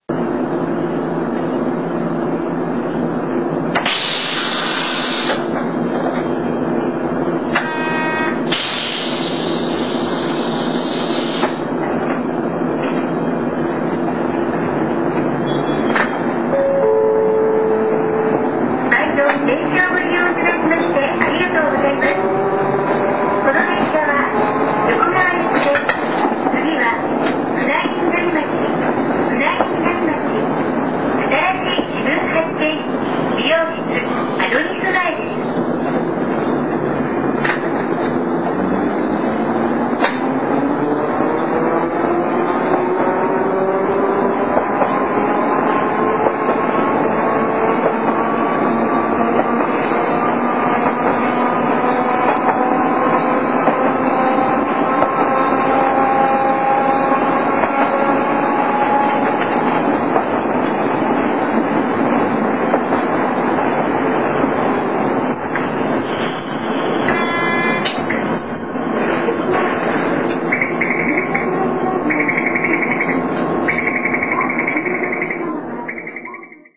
■車内で聴ける音■